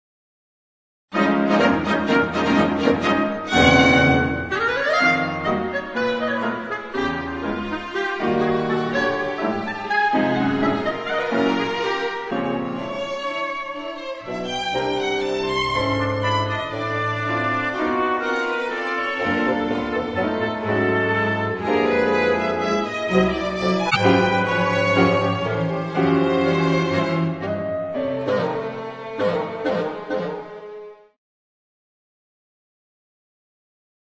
für fünf Bläser, Klavier und Streicher
Bläserbesetzung: Fl., Ob., Bb-Klar., Fag., Bb-Trpt.